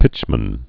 (pĭchmən)